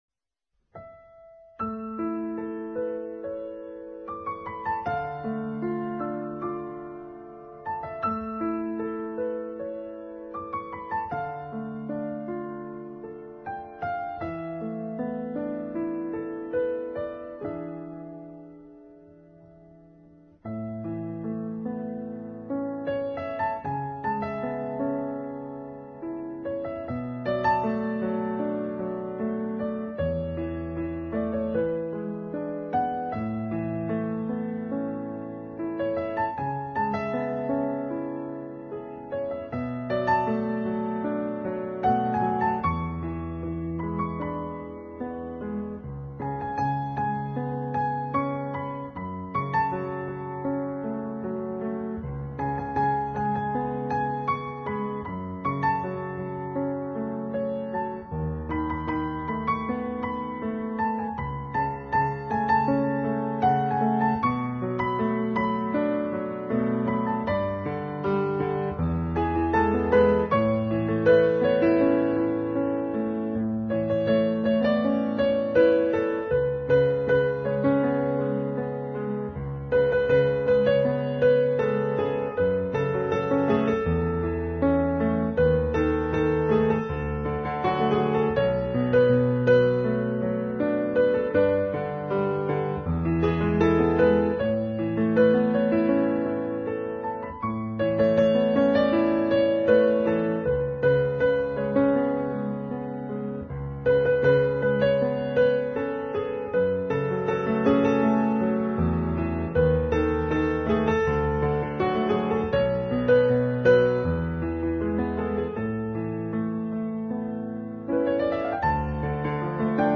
纯音乐
DSD数码混制